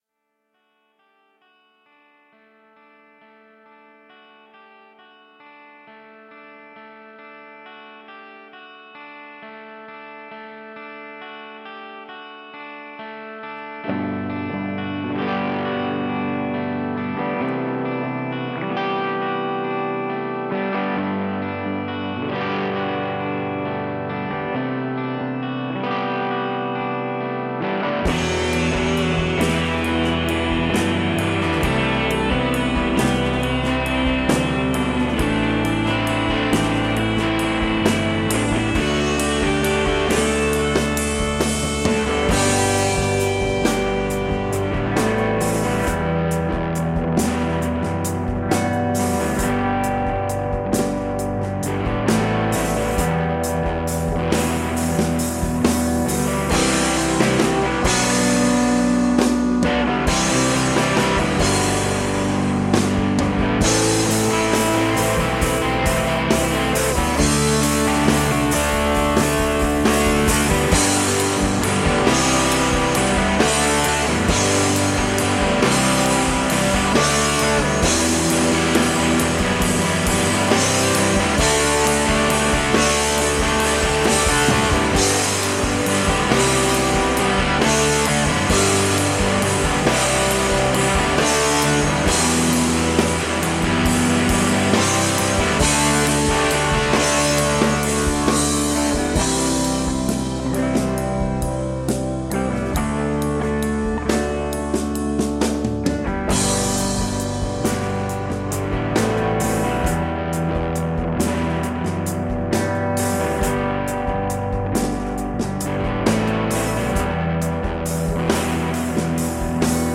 High energy rock and roll.
at a warehouse in Northern Virginia
Tagged as: Hard Rock, Metal, Punk, High Energy Rock and Roll